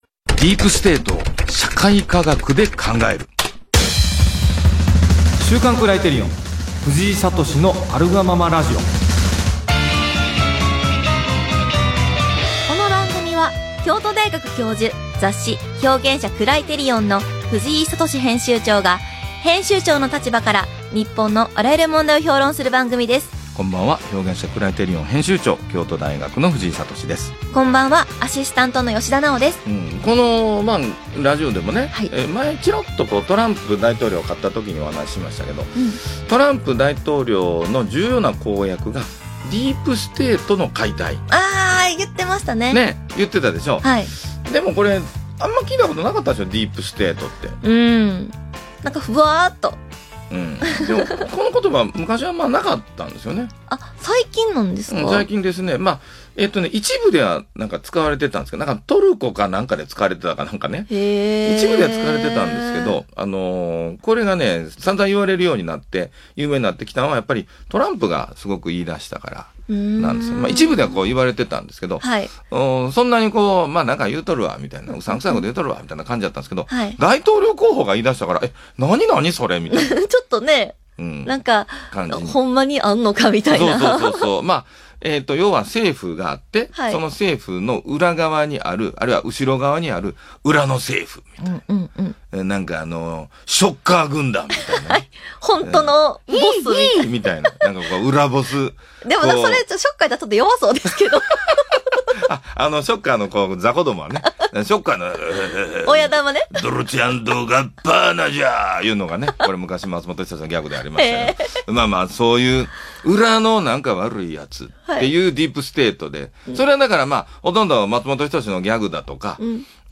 【ラジオ】「ディープステート」を社会科学で考える